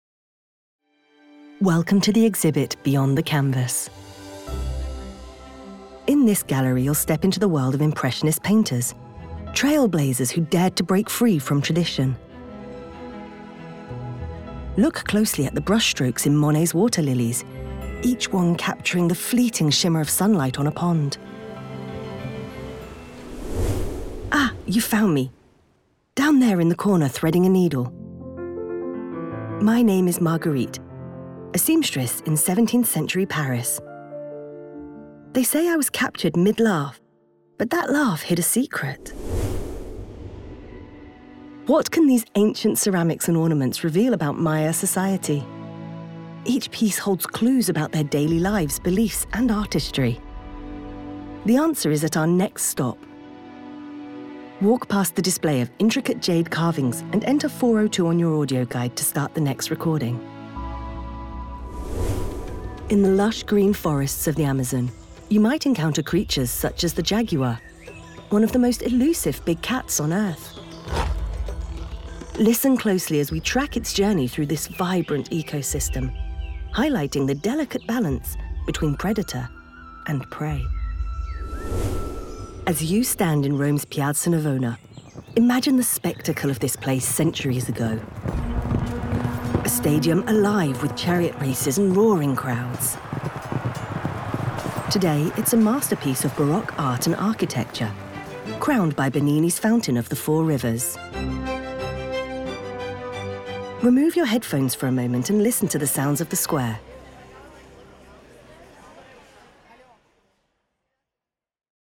Anglais (Britannique)
Commerciale, Jeune, Naturelle, Amicale, Corporative
Guide audio